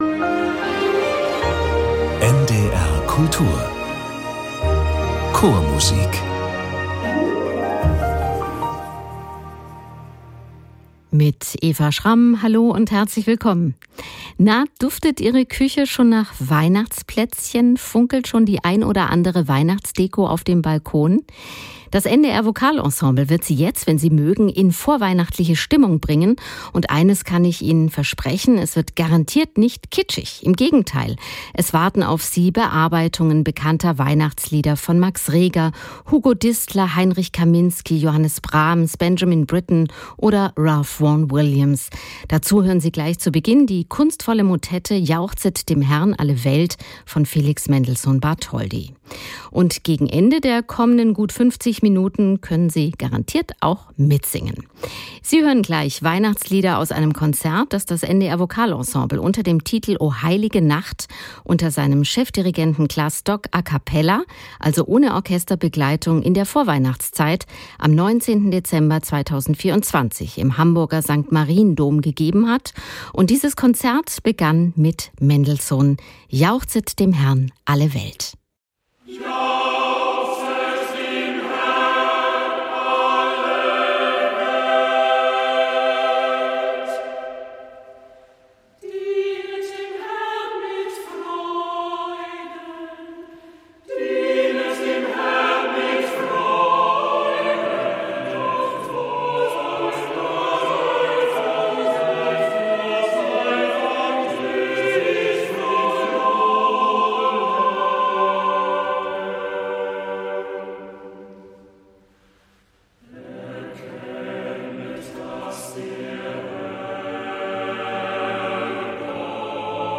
Chormusik aus allen Jahrhunderten, aus allen Ländern und Zeiten und Chöre aus aller Welt.